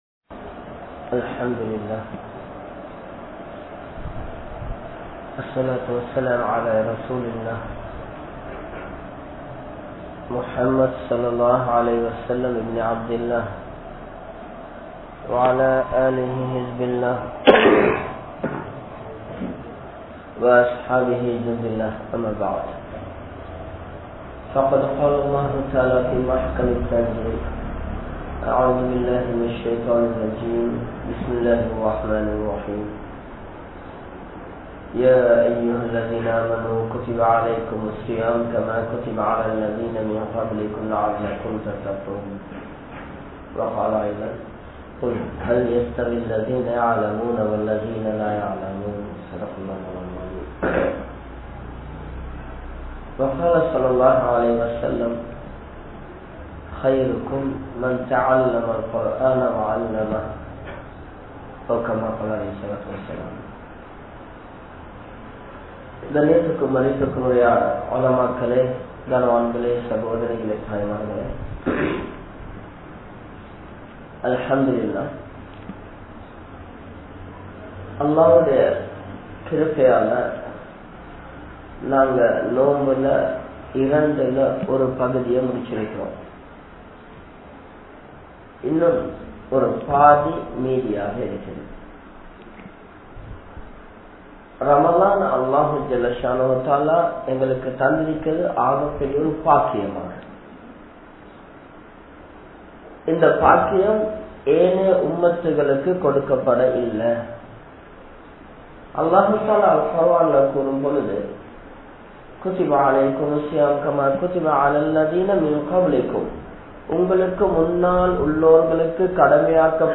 Indru Seeralium Samooham (இன்று சீரழியும் சமூகம்) | Audio Bayans | All Ceylon Muslim Youth Community | Addalaichenai
Saliheen Jumua Masjidh